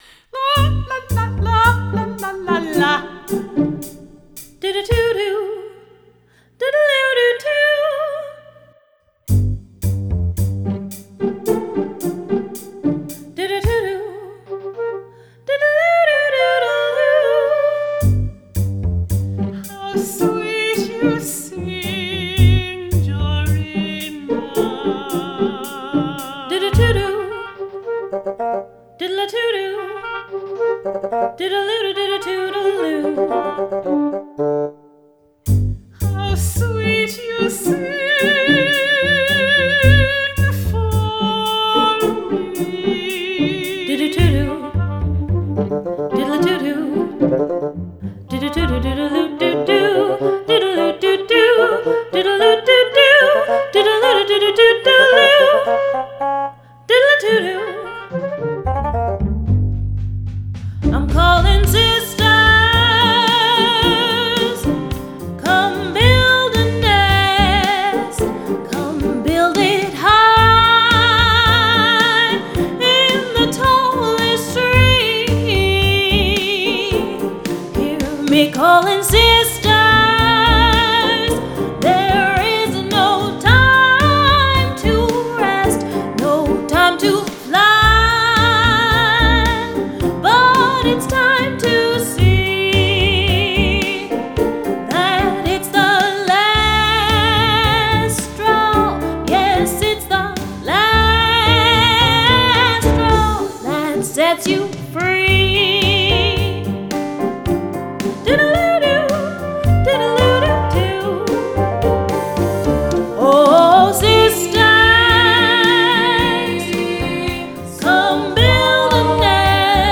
Some talented singers conveyed the story excellently. In addition, three human virtuoso instrumentalists added realism to the computer-played tracks.
mezzosoprano
soprano
tenor
Supplementing the midi orchestra:
violin
flute
bassoon